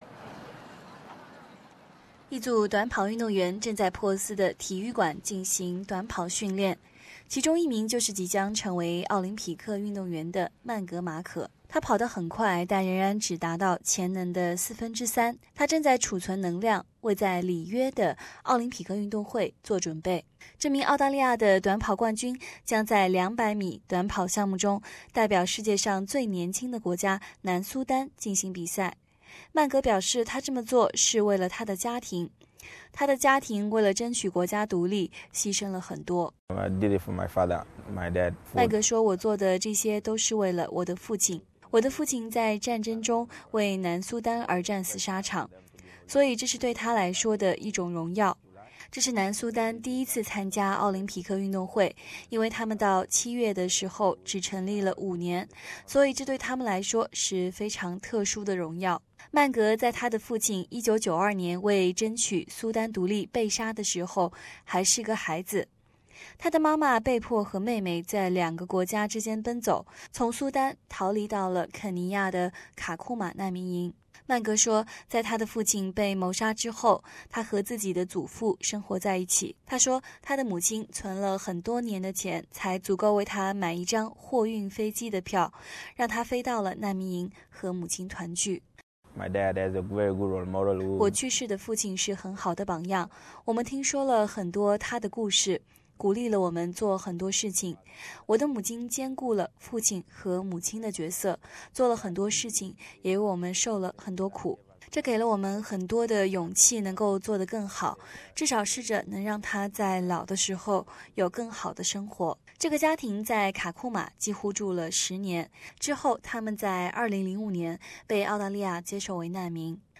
对这名来到澳大利亚之后开启短跑事业的短跑运动员生活详细报道